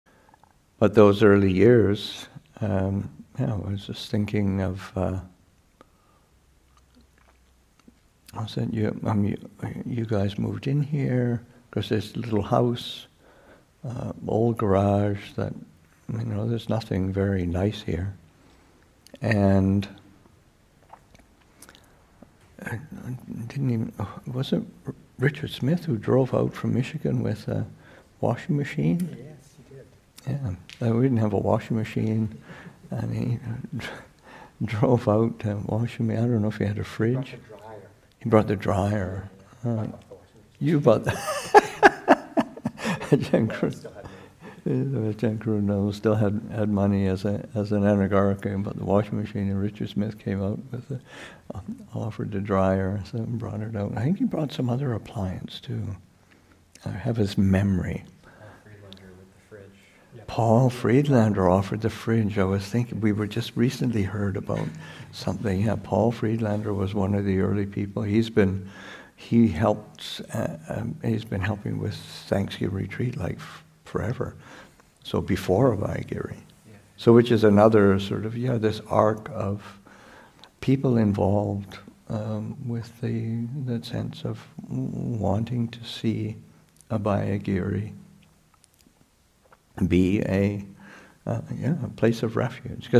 Recollection: Generous contributions in the early days of Abhayagiri.